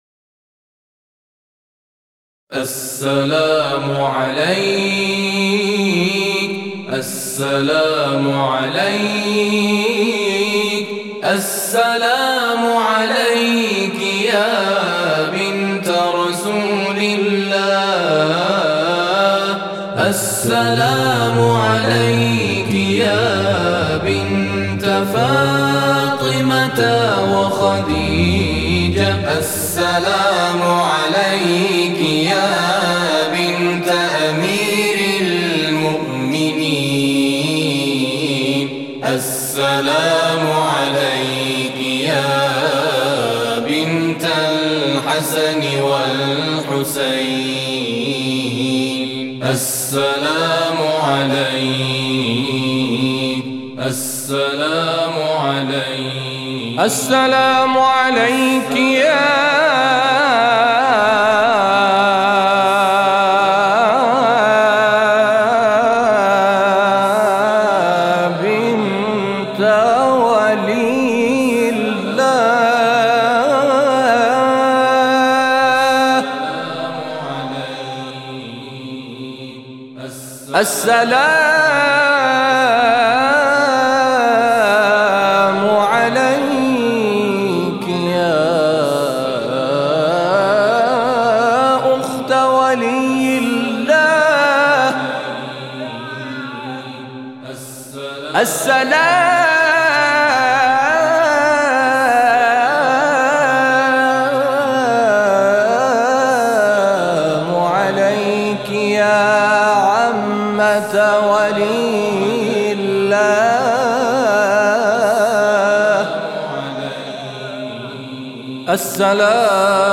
نغمات آئینی